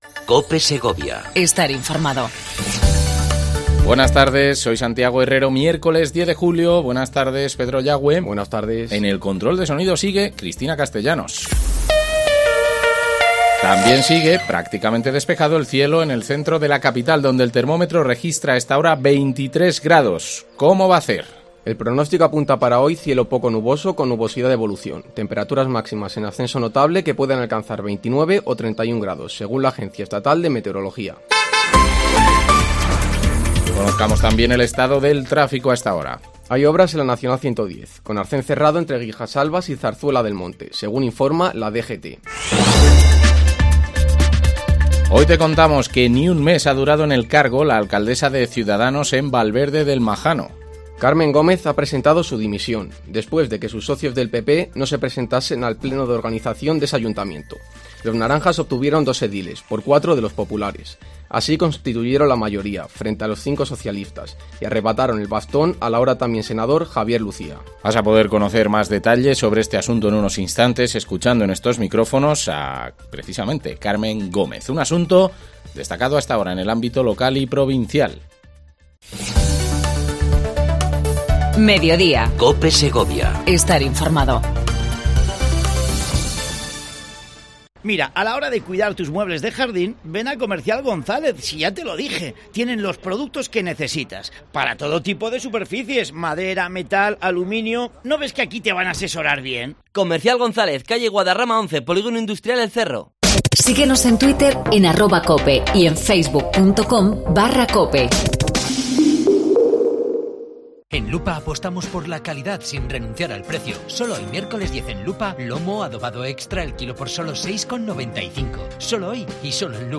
AUDIO: Entrevista a Carmen Gómez, actual Alcaldesa de Valverde del Majano que ha presentado su dimisión.